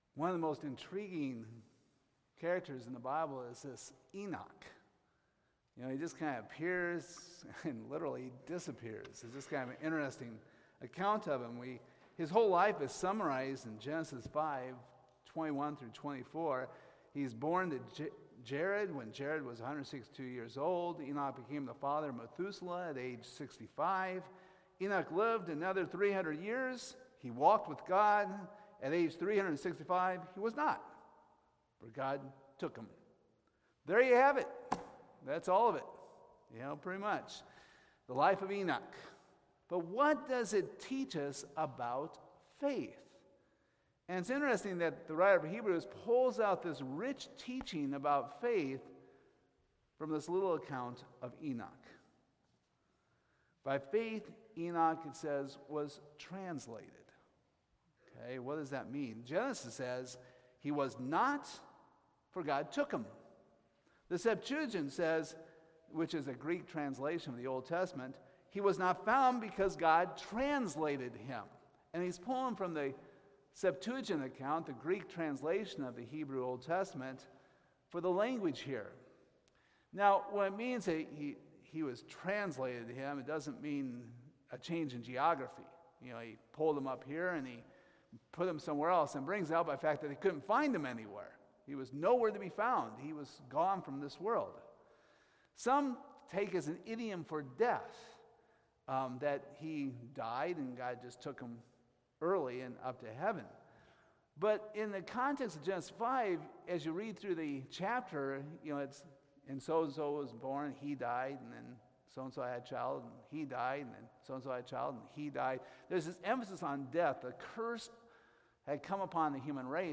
Hebrews 11:1-6 Service Type: Sunday Morning How does Enoch's life show faith?